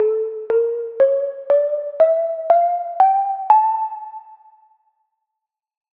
Phrygian Maj.
2025-kpop-scale-dom.mp3